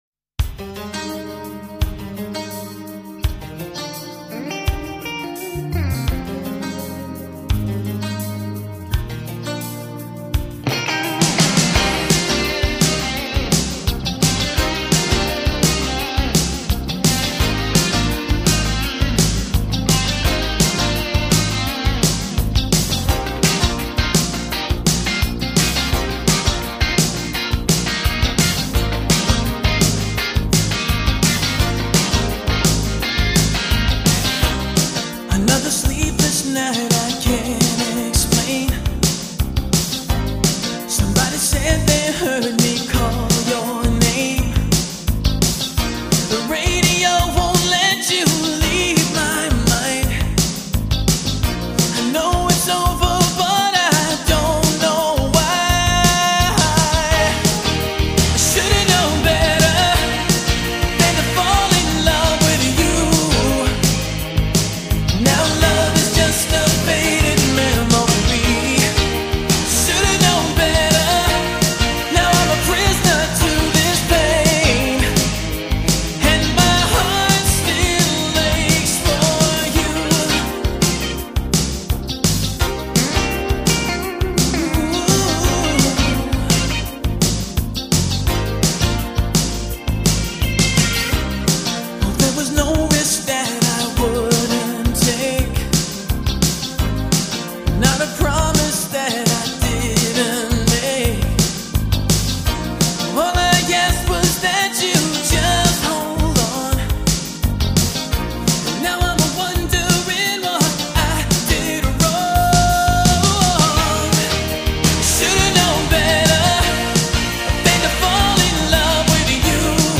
追忆年少爱恋时光的Top 5摇滚劲歌
全美热门单曲榜第三名／主流摇滚榜第七名